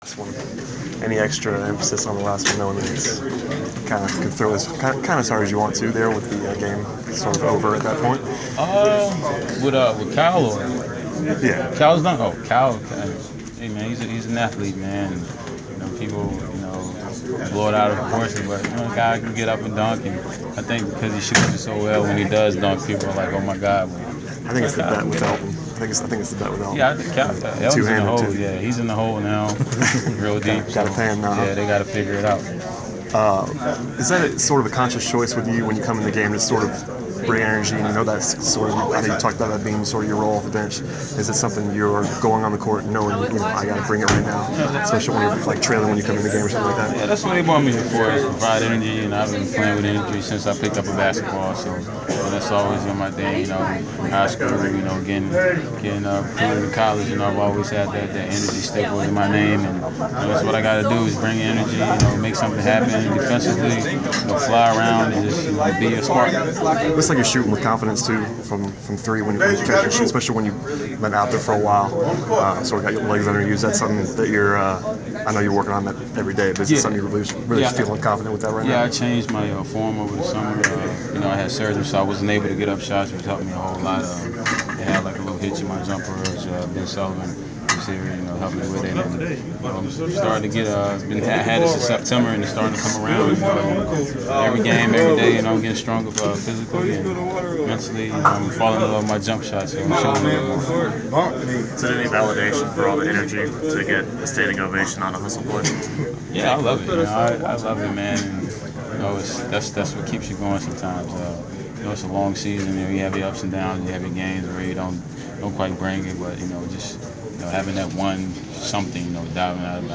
Inside the Inquirer: Postgame interview with Atlanta Hawk Kent Bazemore (1/23/15)
We caught up with Atlanta Hawks’ guard Kent Bazemore following his team’s 103-93 home victory over the Oklahoma City Thunder on Jan. 23. Topics included the team’s franchise-record 15-game winning streak.